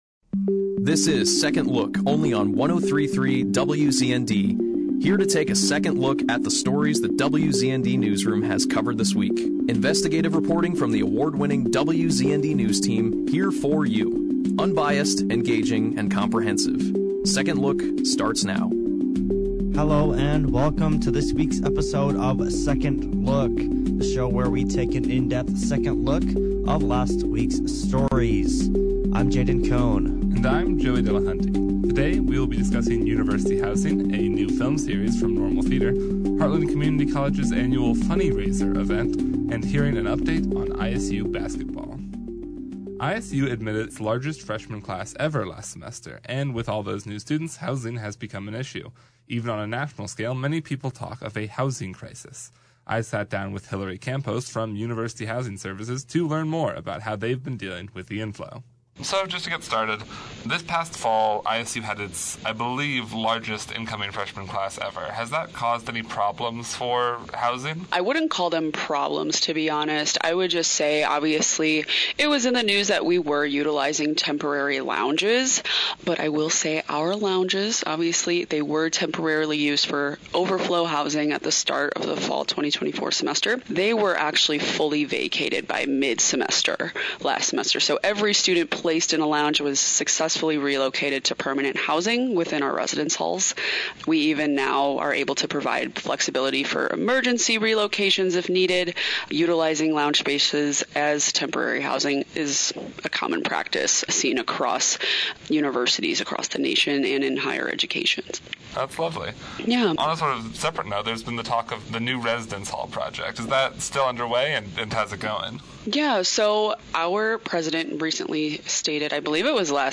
The show opened with an interview